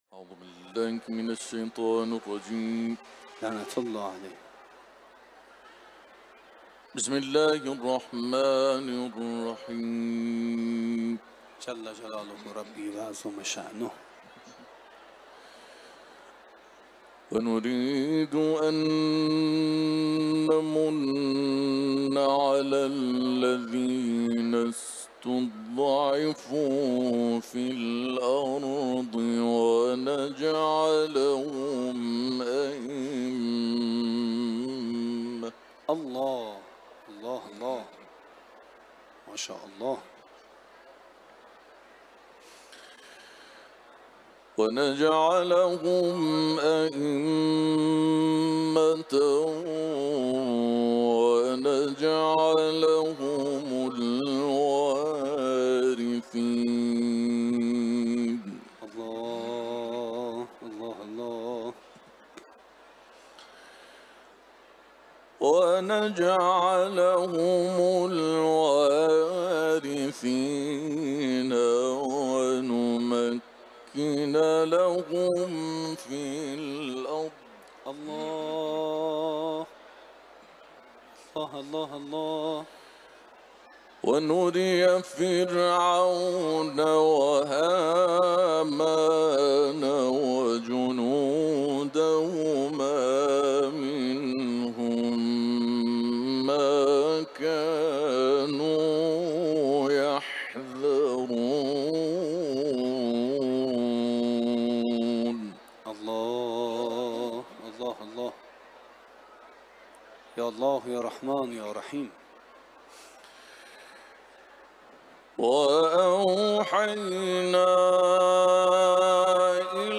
قاری بین‌المللی قرآن
در محفل قرآنی حرم مطهر رضوی
تلاوت قرآن